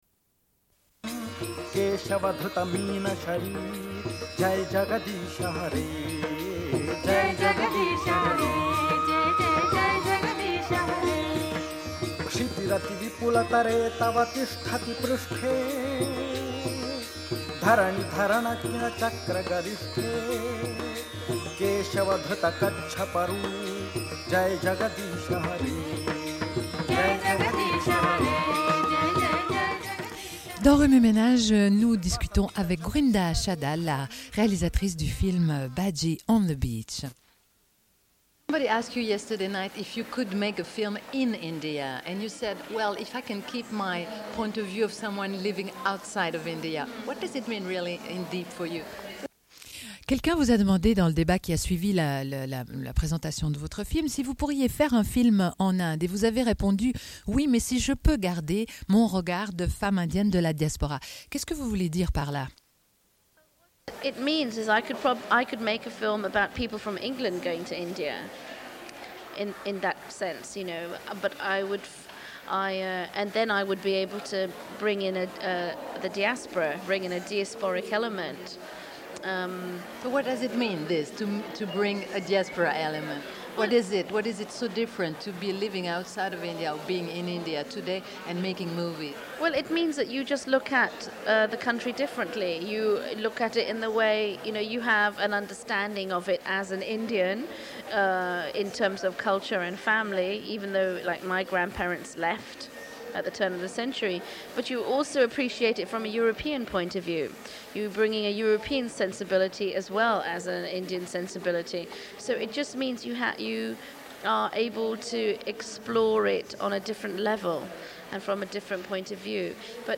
Une cassette audio, face A